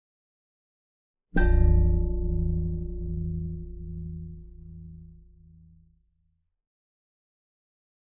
ominous-bell-001.ogg